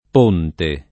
p1nte] s. m. — freq. come top. o elem. di toponimi variam. composti: P. a Ema (Tosc.), P. Caffaro (Lomb.), Pontecurone (Piem.), P. di Brenta (Ven.); P. San Luigi (Lig.), P. San Giovanni (Umbria); P. di Legno (Lomb.); P. dell’Olio (E.-R.); P. in Valtellina (Lomb.), P. nelle Alpi (Ven.); P. di Falmenta (Piem.), P. a Poppi (Tosc.), la Strada dei Sette Ponti (cfr.